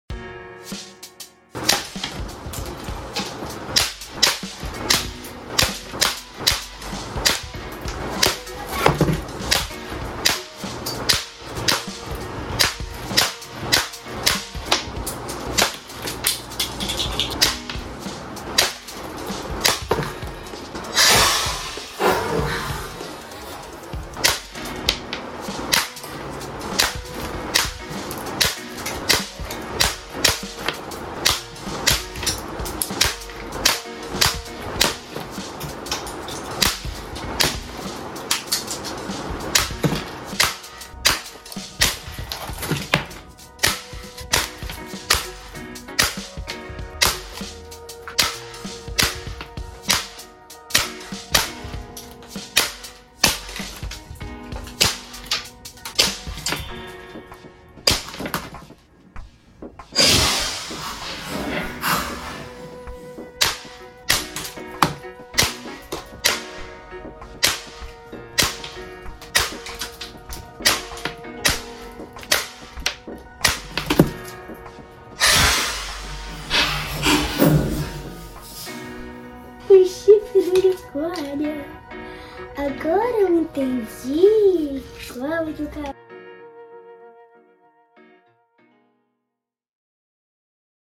O vídeo é uma sequência emocionante de tiros precisos e devastadores que vão lentamente destruindo o Unicórnio Rosa de Brinquedo.
Sem narração ou interrupções, você verá diretamente o confronto entre a delicadeza do Unicórnio Rosa e a força brutal da Carabina de Pressão.